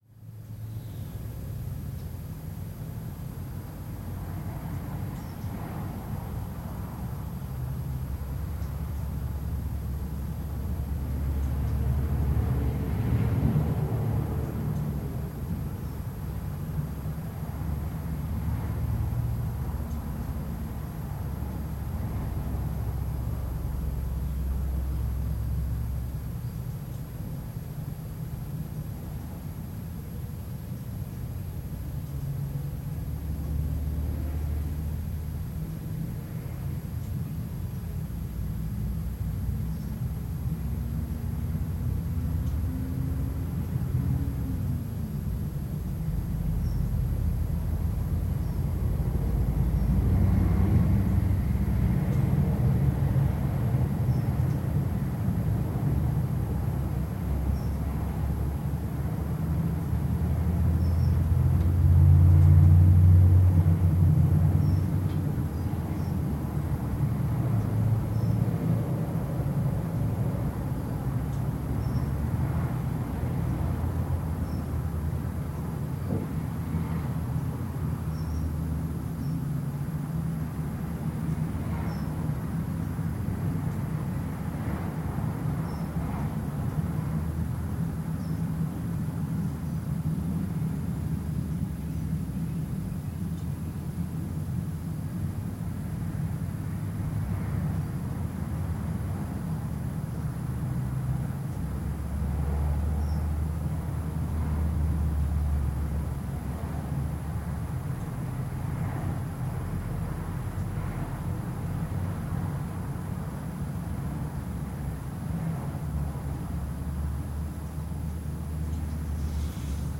Звуки комнаты
Тихий гул машин вдали за окном пустой комнаты